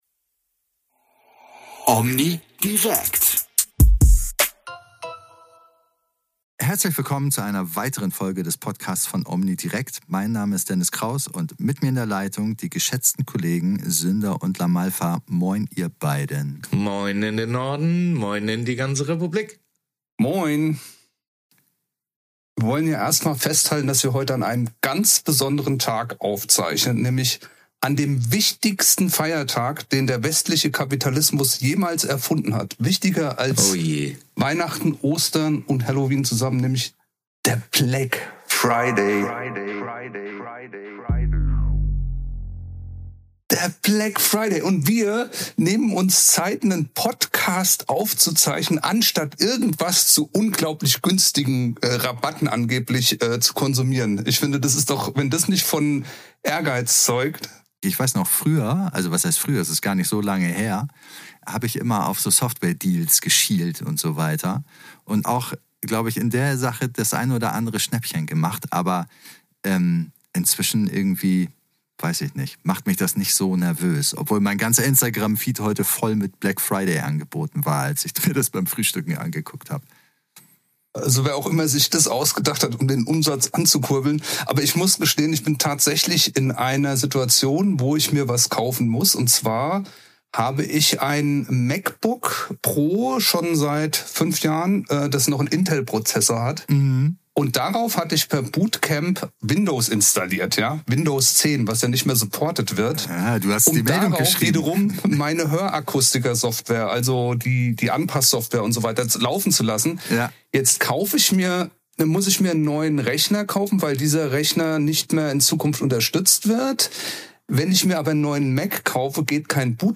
Unsere drei Podcaster zeigen dieses Mal einen ganz besondere Widerstandskraft. Nicht widerstehen konnten sie hingegen ihren Erinnerungen an drei Tage EUHA-Kongress.